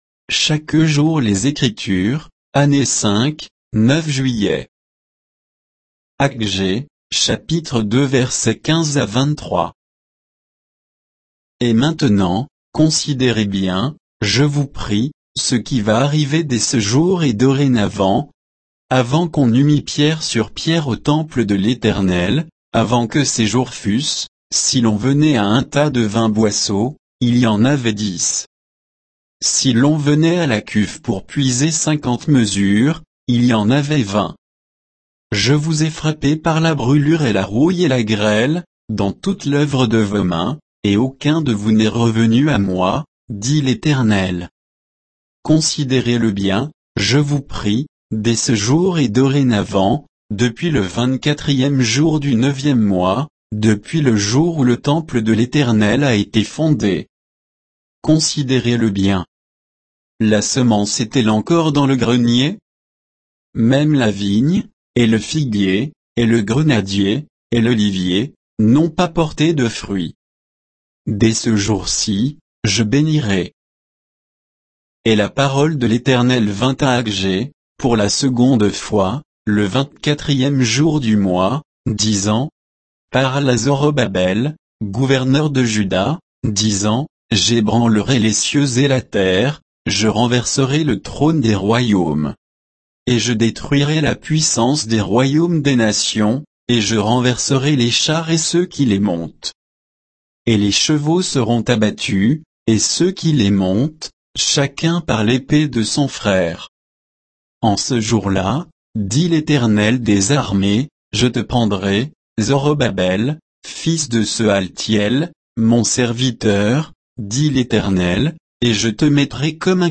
Méditation quoditienne de Chaque jour les Écritures sur Aggée 2